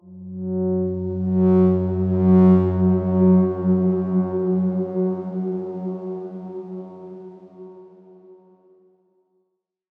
X_Darkswarm-F#2-ff.wav